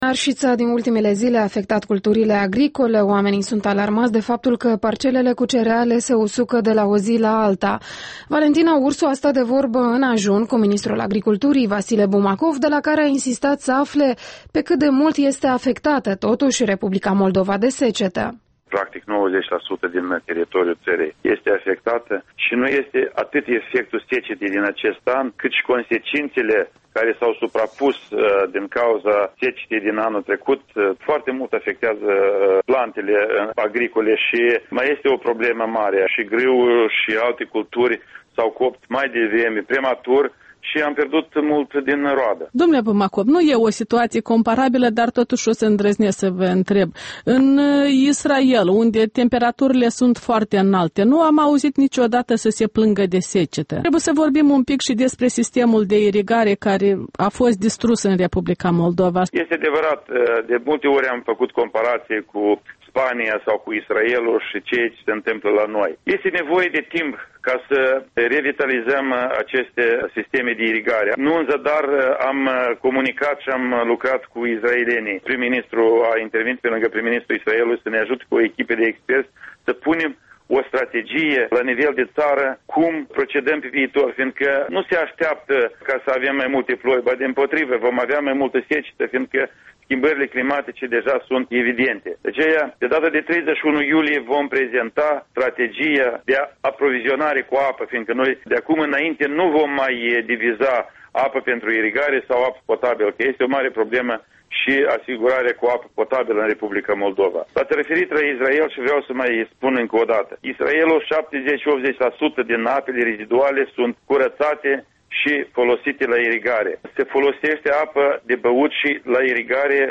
Interviul matinal la EL: cu Vasile Bumacov despre arșiță, irigare și consecențe pentru agricultură